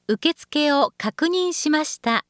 ホームページ作成で利用できる、さまざまな文章や単語を、プロナレーターがナレーション録音しています。
ナレーション： 受付を確認しました サックス教室 レッスン 京都 滋賀 大津市 ホームページ制作 見直し 京都市 無料相談会 ホームページ変更 更新 京都 ホームページ作成 京都 インターネットホームページ 活用 相談 京都市 ホームページ制作会社 京都 ナレーション録音スタジオ パワーポイント制作 披露宴 出張演奏 京都 「アイデア・エコノミー」 「アイデア・エコノミー」は「アイデアの経済」のこと。